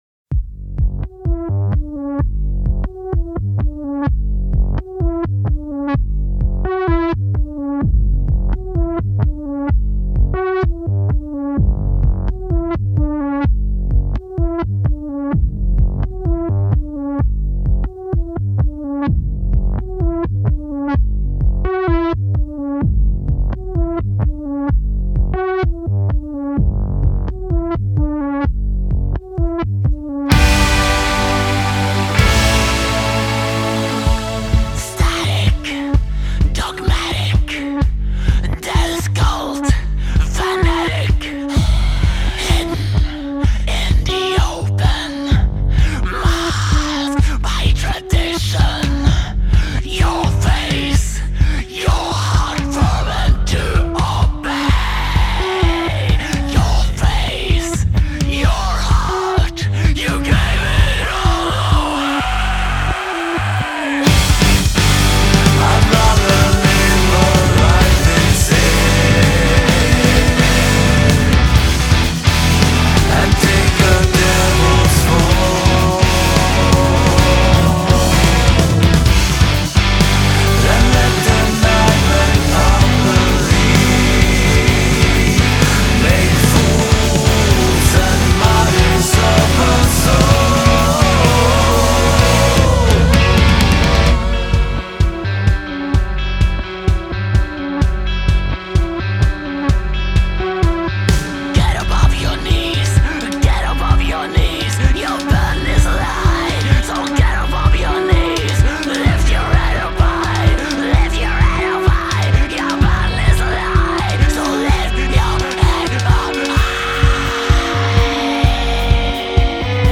Genre: Experimental / Progressive Metal